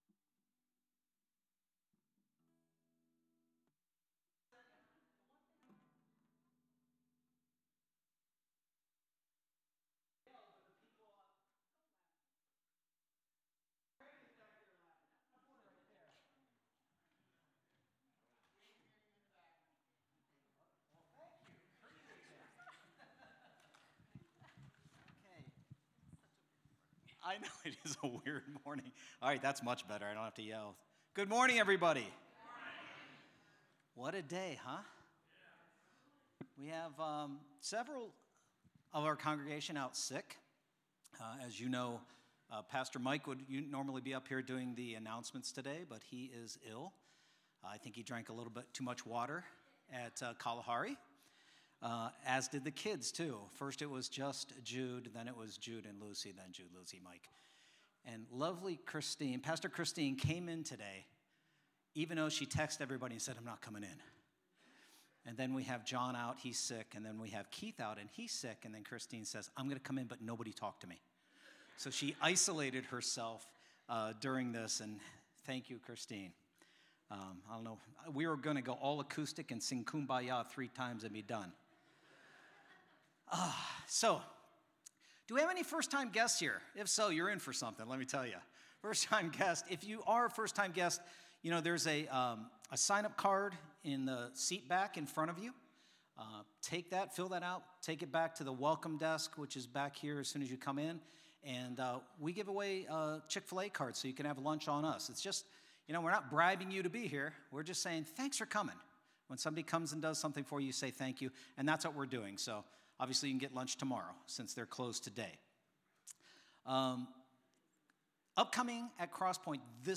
Cross Pointe Church — He Calls Me Fred | Guest Speaker